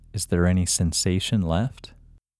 IN – Second Way – English Male 22